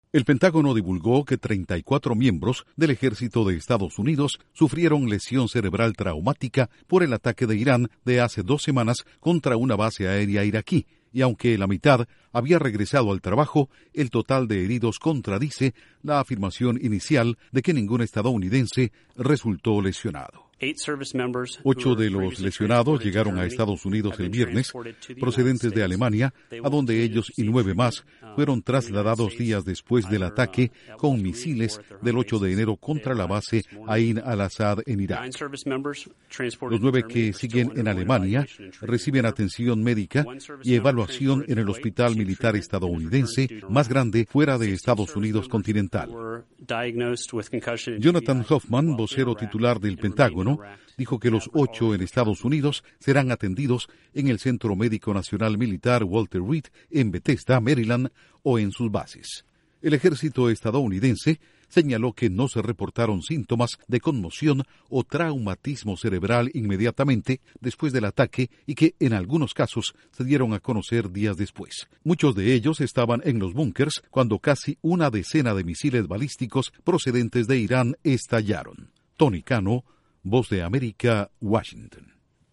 Pentágono: 34 soldados de Estados Unidos sufrieron lesión cerebral en ataque iraní. Informa desde la Voz de América en Washington
Duración: 1:22 Con declaraciones de Jonathan Hoffman/Vocero del Pentágono